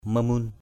/mə-mu:n/ mamun mm~N [Cam M] (đg.) cúng lễ = faire un sacrifice dans certaines circonstances = make a sacrifice in certain circumstances.